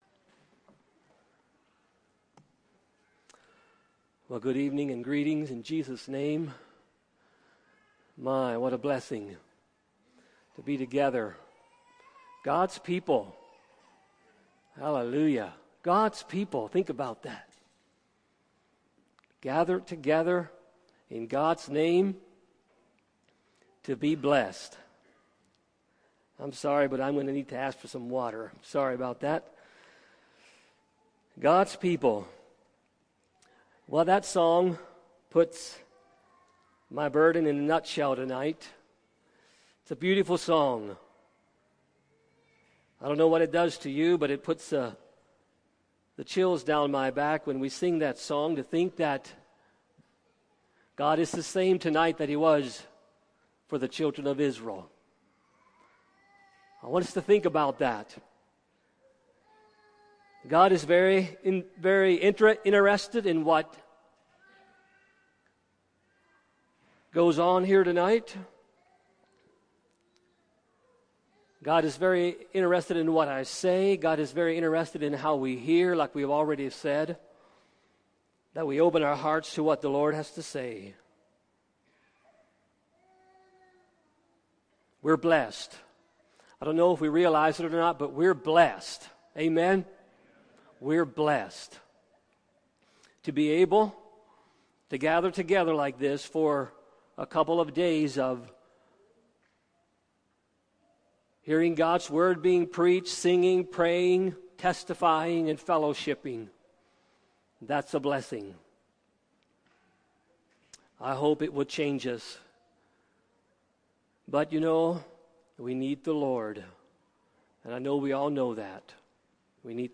Friday Evening Message from 2017 Fellowship Meetings